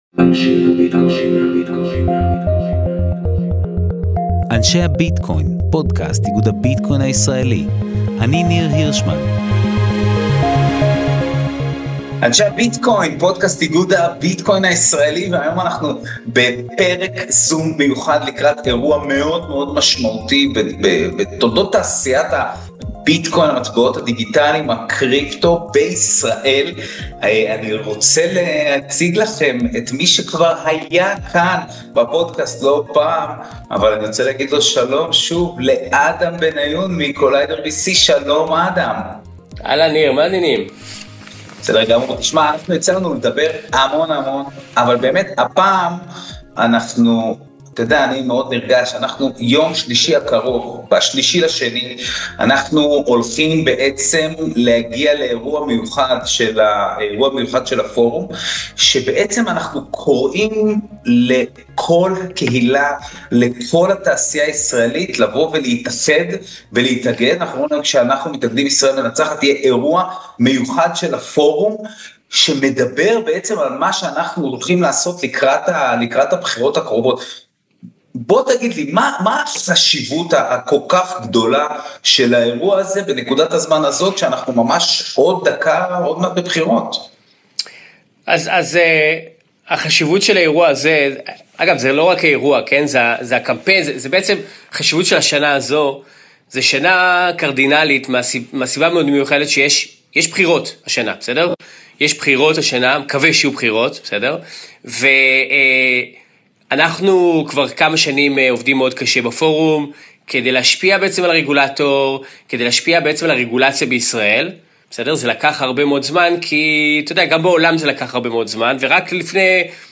בשיחה צפופה